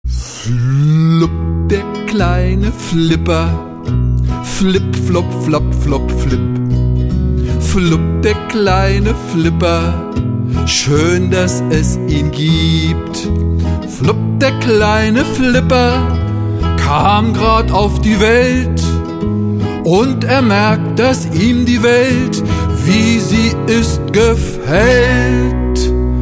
Lieder in unserer und eurer Sprache
Kinderlieder